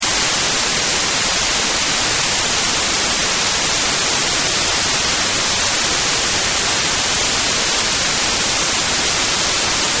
White Noise_10sec_L+R
wite_noise_10sec.mp3